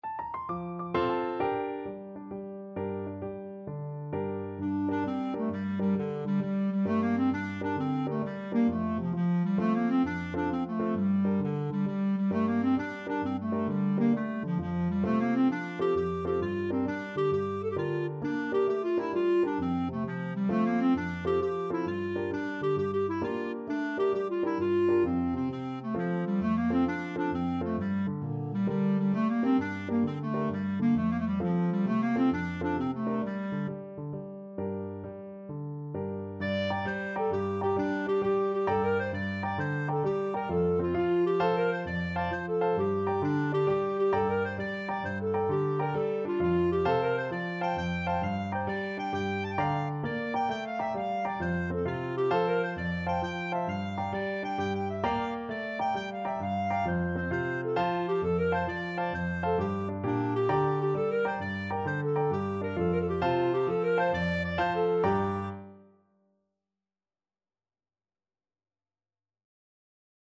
G minor (Sounding Pitch) A minor (Clarinet in Bb) (View more G minor Music for Clarinet )
9/8 (View more 9/8 Music)
Clarinet  (View more Intermediate Clarinet Music)
Traditional (View more Traditional Clarinet Music)
Irish